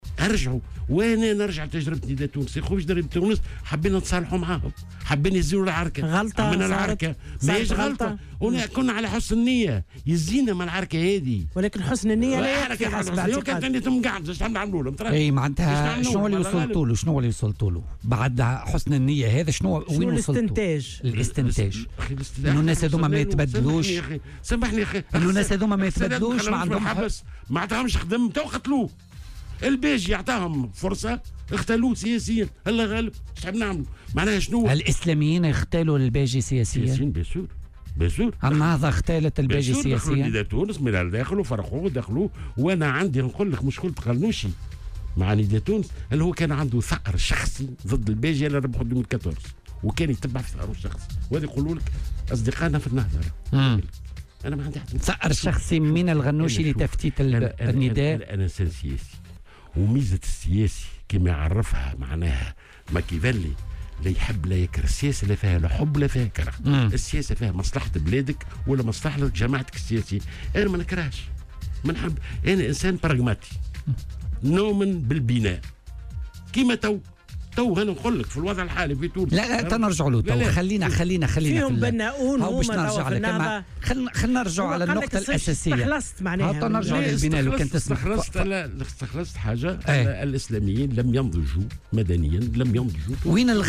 وأضاف في مداخلة له اليوم في برنامج "بوليتيكا" على "الجوهرة أف أم" أن الباجي قائد السبسي أعطاهم فرصة ومدّ يده لهم لكنهم "اغتالوه سياسيا" من خلال اختراق حزب نداء تونس وتشتيته، حسب قوله.